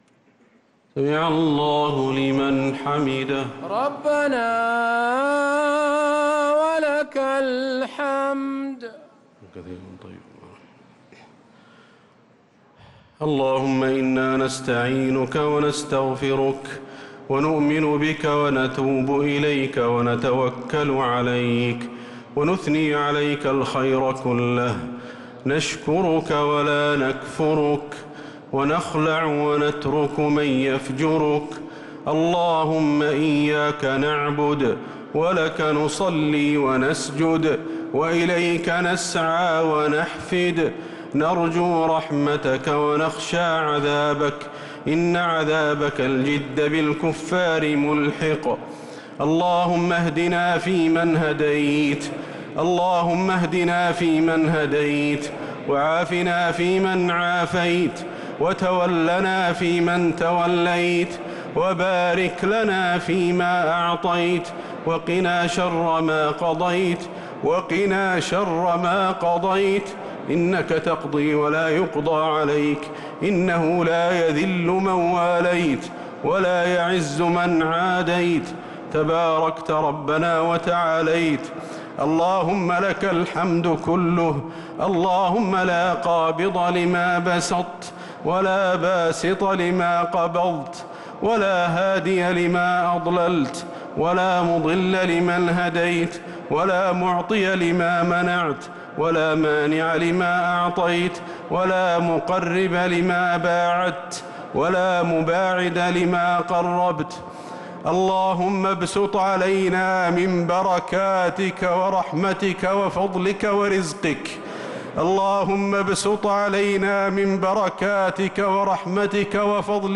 دعاء القنوت ليلة 22 رمضان 1446هـ | Dua 22nd night Ramadan 1446H > تراويح الحرم النبوي عام 1446 🕌 > التراويح - تلاوات الحرمين